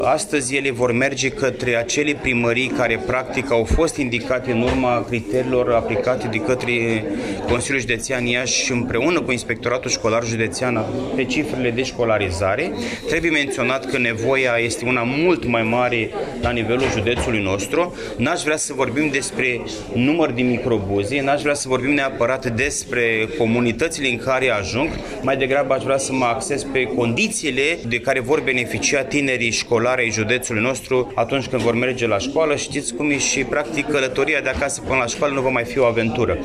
Președintele Consiliului Județean Iași, Costel Alexe, a declarat că urmează o nouă licitație pentru achiziționarea a încă trei microbuze, destinate comunelor Belcești, Mironeasa și Tătăruși.